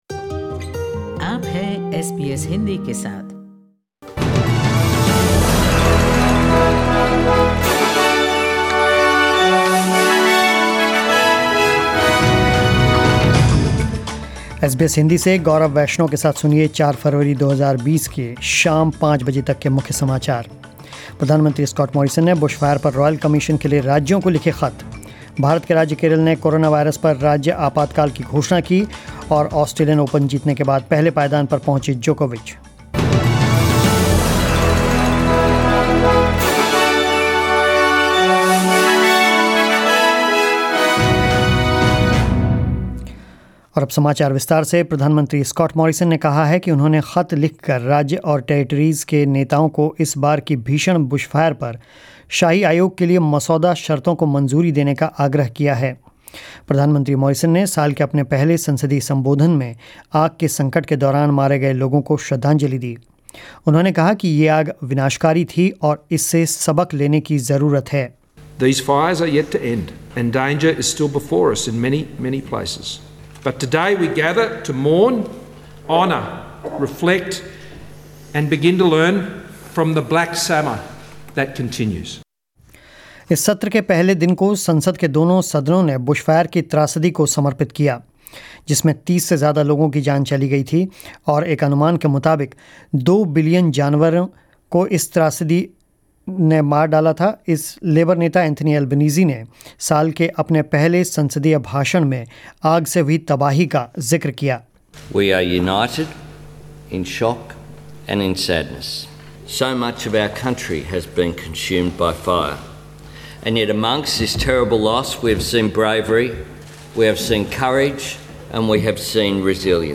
News in Hindi 04 Feb 2020